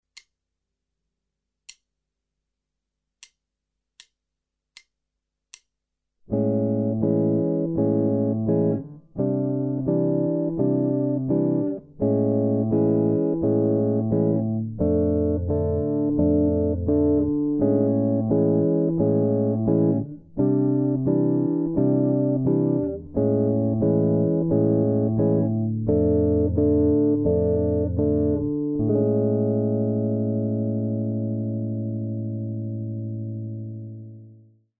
• Stroke type: Free stroke only
• Finger combinations: p+i+m, p – i – m
I couldn’t bring myself to inflict this on you as well, so here are a couple of similar fingerpicking exercises but with some more interesting chord progressions.
fingerstyle-tutorial-guitar-fingerpicking-exercises-jazz-ex-4-3.mp3